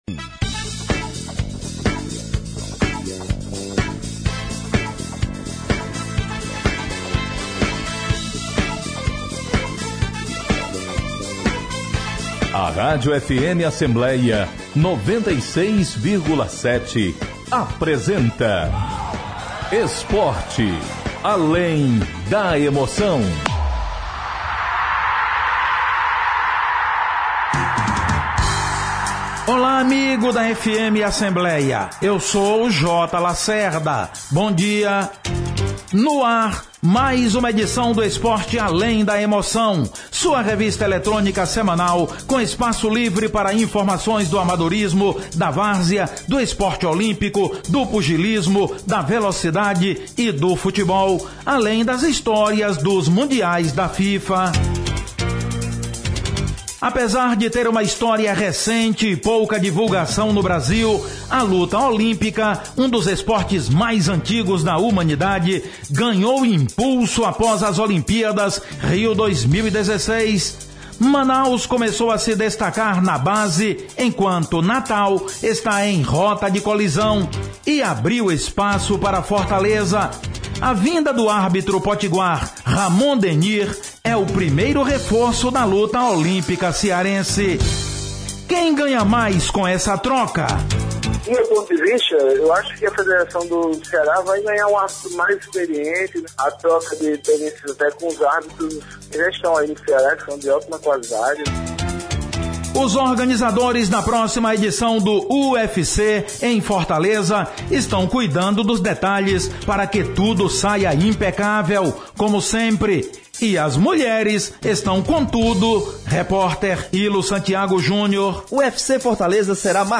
O programa Esporte Além da Emoção deste domingo (19/02) traz para os ouvintes da rádio FM Assembleia (96,7 MHz) uma entrevista com o árbitro internaci...